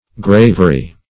Search Result for " gravery" : The Collaborative International Dictionary of English v.0.48: Gravery \Grav"er*y\, n. The act, process, or art, of graving or carving; engraving.